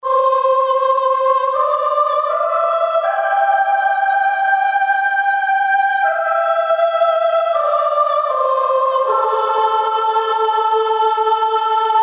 PS合唱团包 女声合唱团 天堂
Tag: 80 bpm Ambient Loops Vocal Loops 2.02 MB wav Key : Unknown